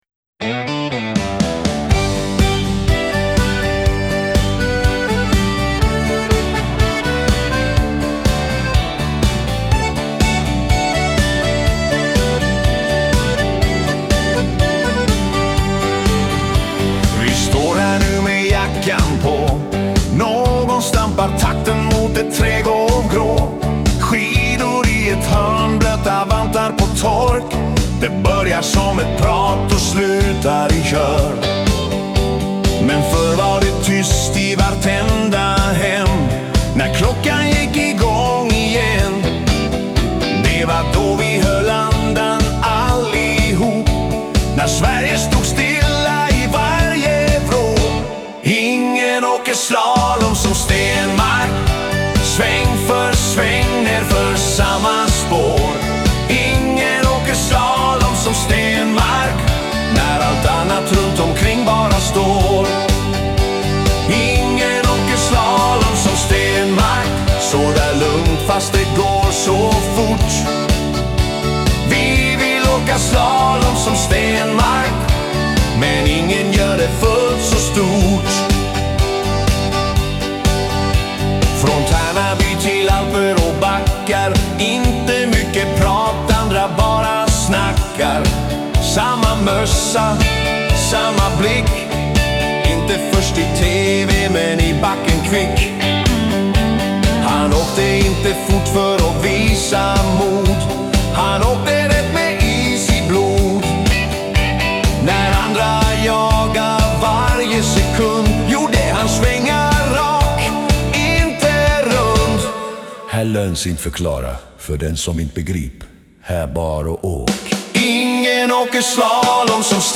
en varm och allsångsvänlig hyllning
svenskt dansband